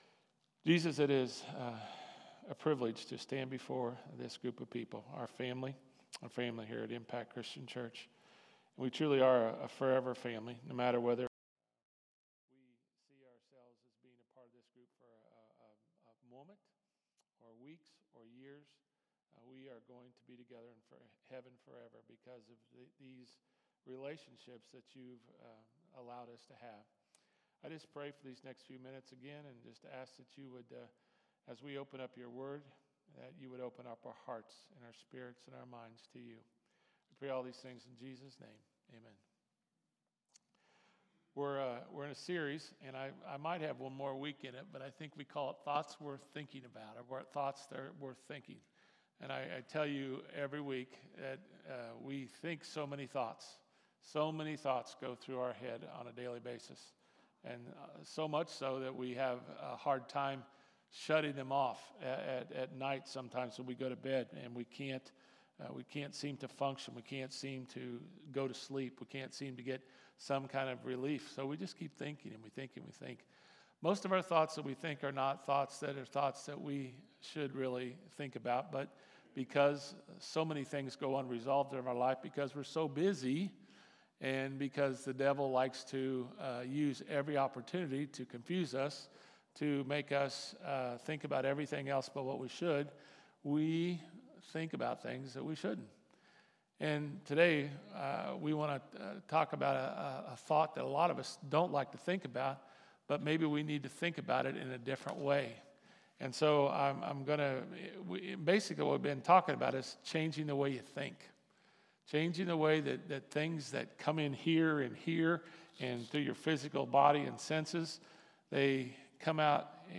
sermon-10.26.mp3